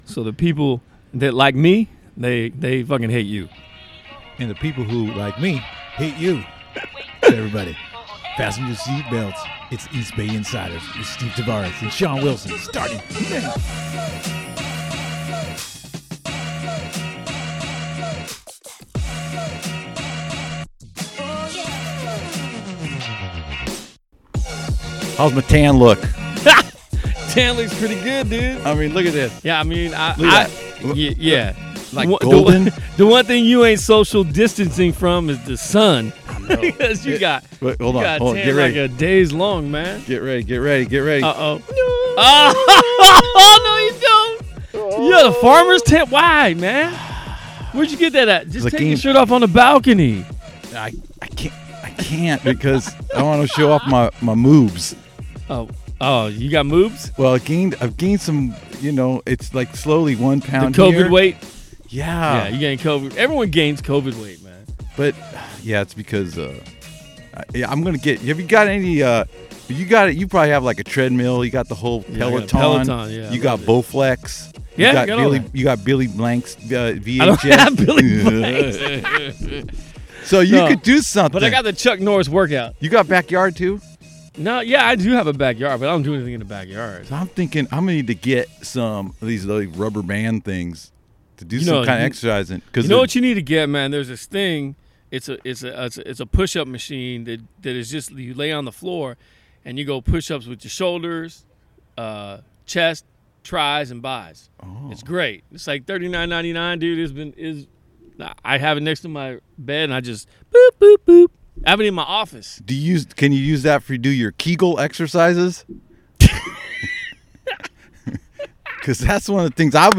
wearing masks and social distancing, are together for the first in-person episode since the pandemic started. Two candidates in San Leandro might be showing how campaign outreach will be done this year. Should 16- and 17-year-olds be able to vote? Plus, A's rent strike.